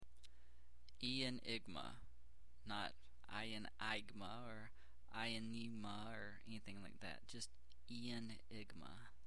the correct pronunciation here.